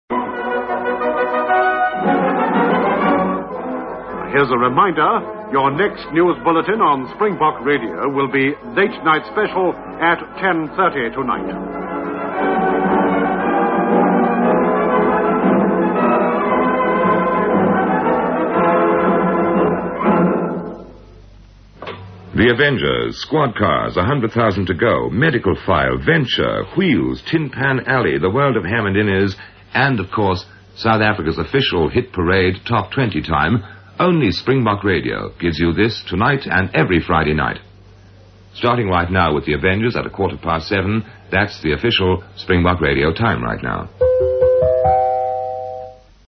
Original Springbok Radio Announcement
joker_05_continuity.mp3